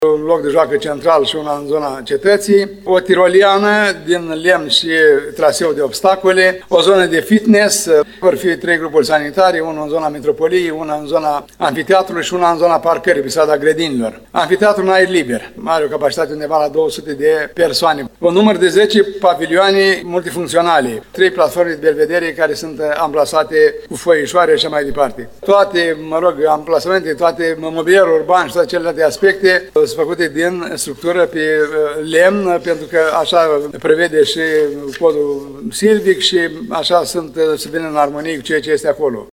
Primarul ION LUNGU a detaliat astăzi principalele dotări propuse pentru Parcul Șipote.